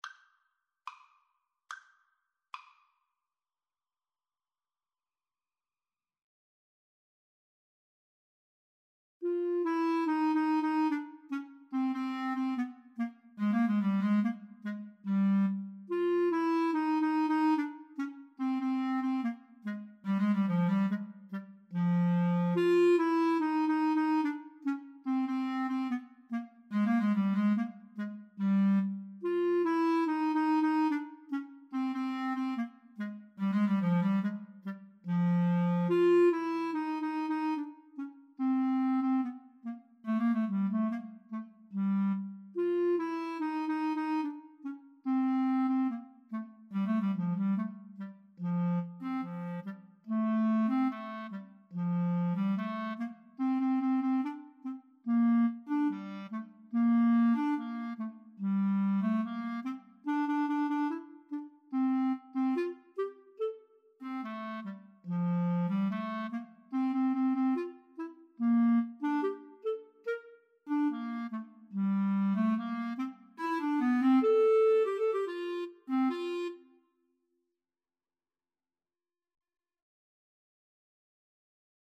Classical (View more Classical Clarinet Duet Music)